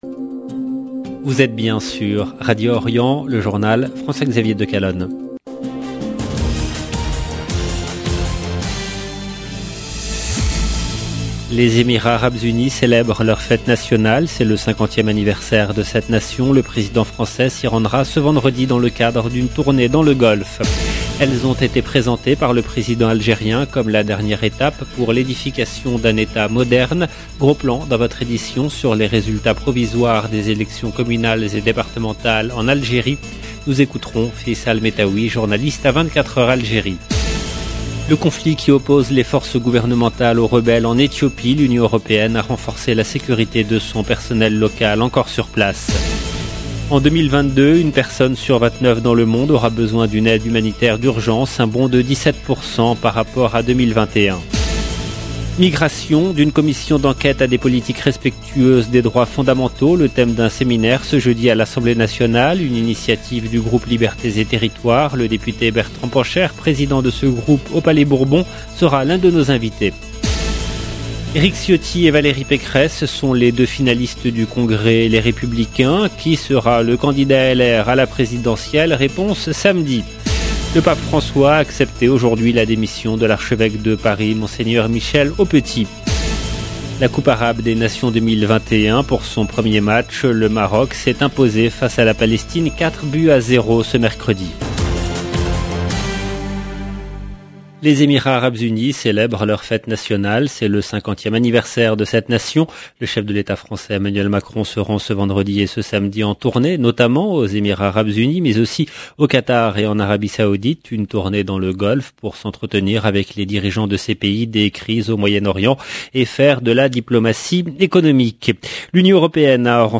EDITION DU JOURNAL DU SOIR EN LANGUE FRANCAISE DU 2/12/2021
Le député Bertrand Pancher, Président de ce groupe au palais Bourbon sera l’un de nos invités. Des retraités ont manifesté aujourd'hui en France pour une augmentation de leurs pensions.